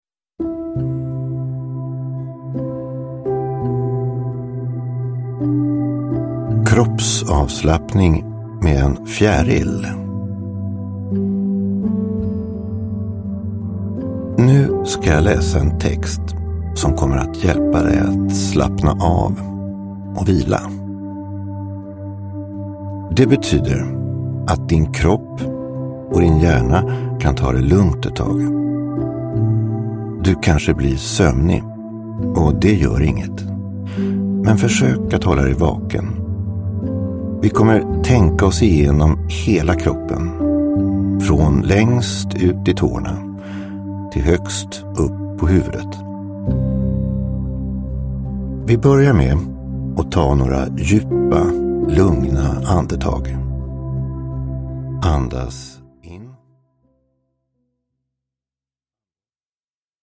Avslappningssagor. Kroppsavslappning med en fjäril – Ljudbok – Laddas ner